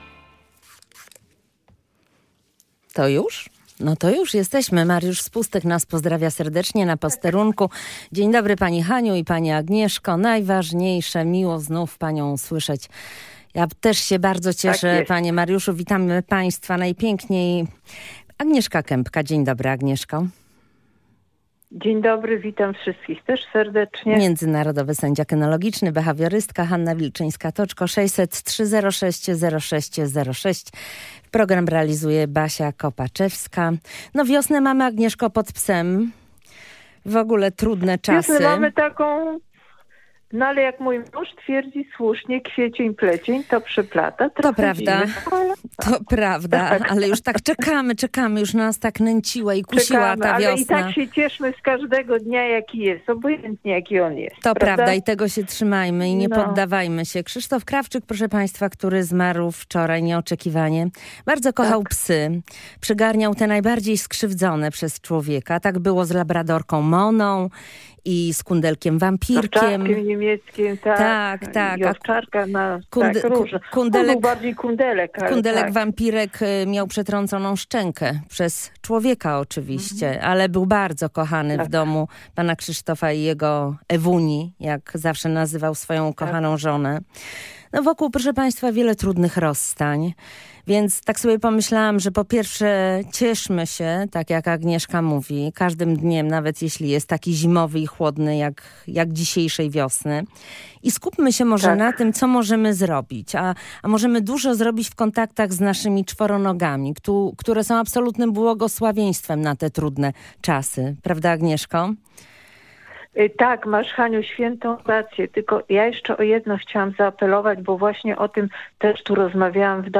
Jak w każdy pierwszy wtorek miesiąca w audycji „Psie Porady” odpowiadaliśmy na pytania słuchaczy. Wśród nadesłanych wiadomości pojawił się SMS, opisujący problem „braku porozumienia” między dwoma kotami.